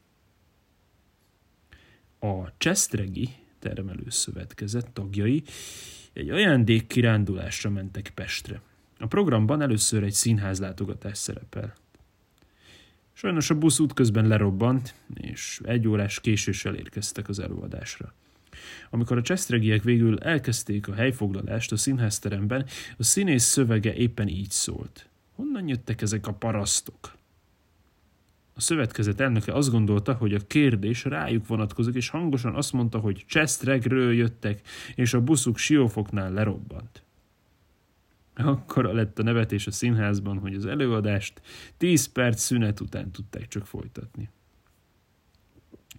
Hazai szerzőket olvasva